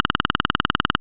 scroll_003.ogg